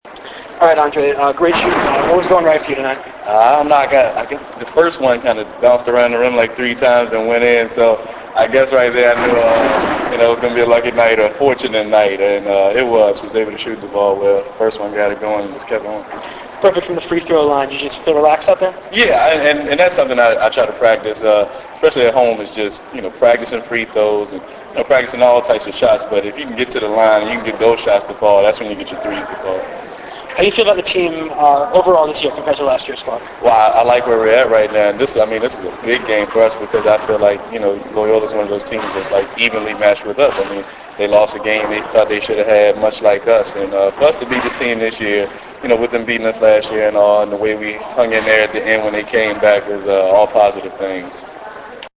Postgame audio: